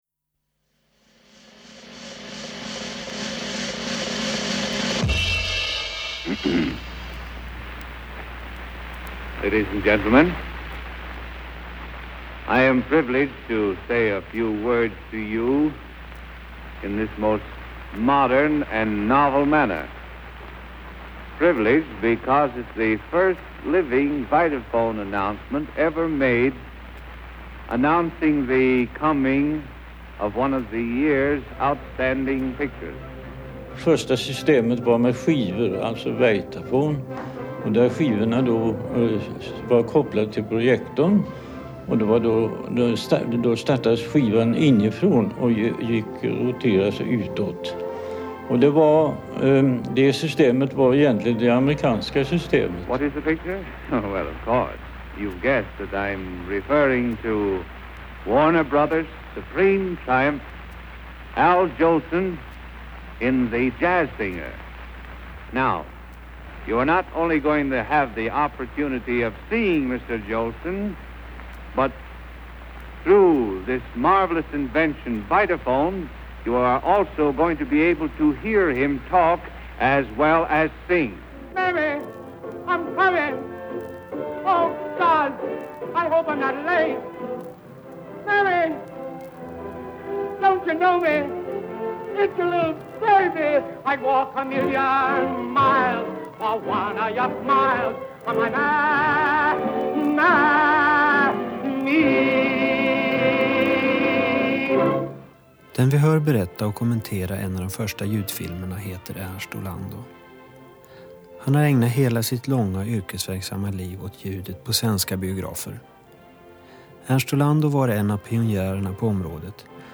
Ljudfilen till den 22 minuter långa intervjun är på 30 MB i MP3-format, 192 Kbps.